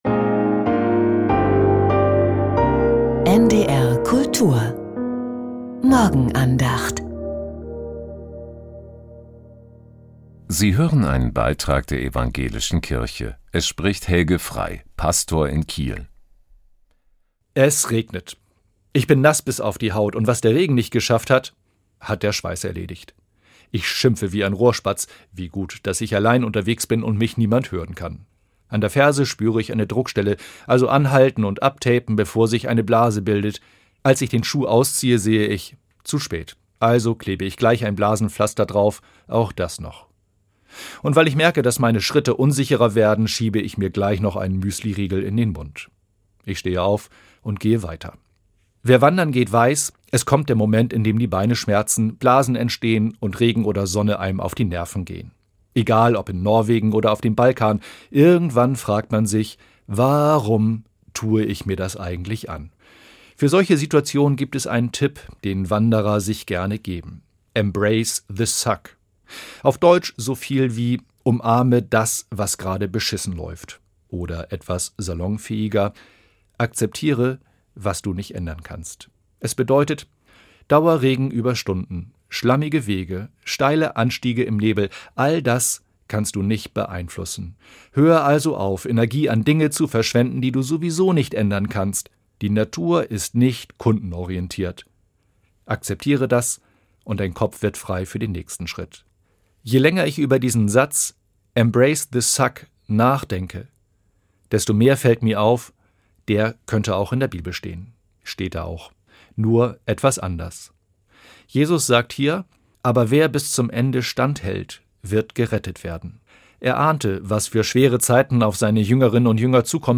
Embrace the suck ~ Die Morgenandacht bei NDR Kultur Podcast